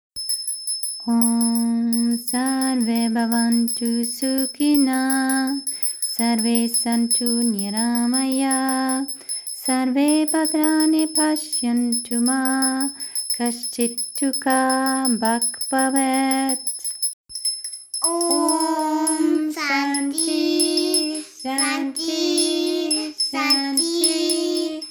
(Sanskrit Mantra; Author unknown)